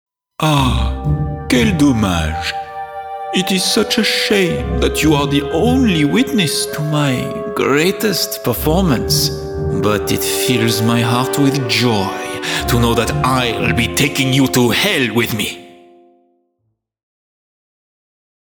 Junge, Natürlich, Warm, Sanft, Corporate
Persönlichkeiten